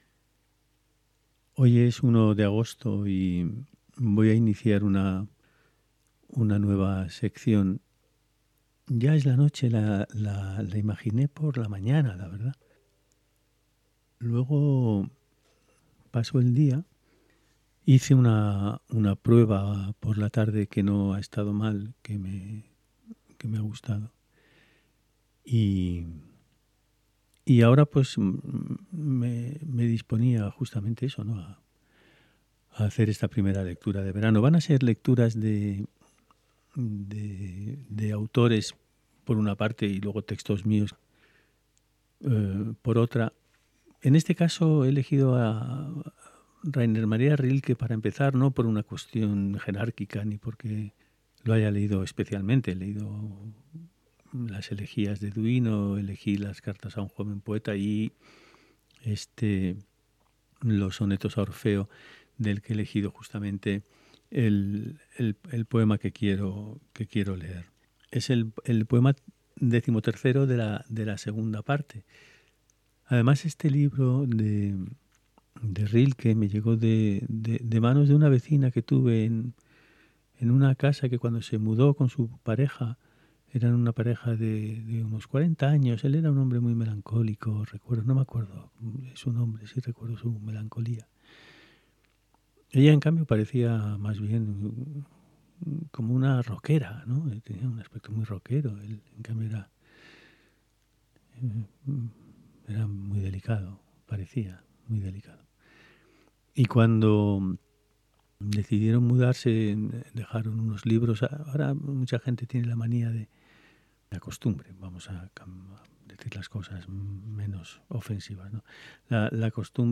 Mosaico romano Inicio esta nueva sección sonora, Lecturas en alta voz , cuando agosto entra y el verano se va a poner a quemar. 01 Poema XIII II parte de Los sonetos a Orfeo de Rilke.mp3 (4.56 Mb)